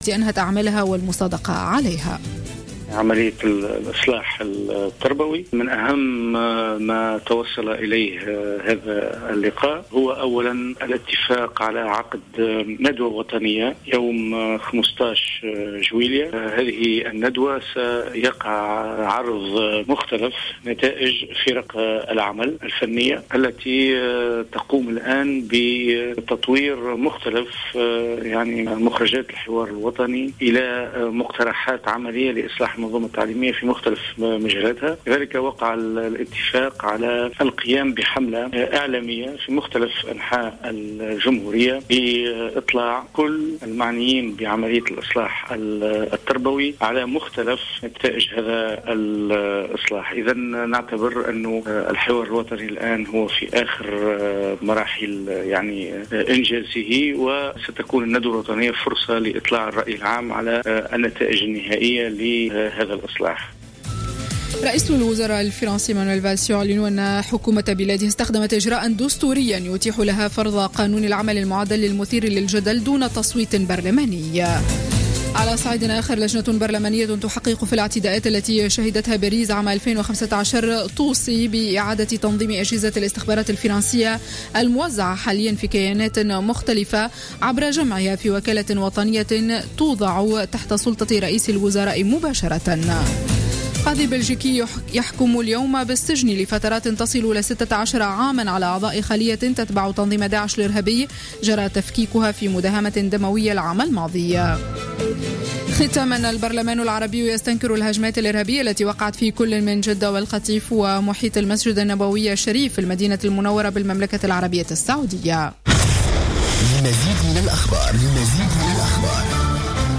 مباشرة من باريس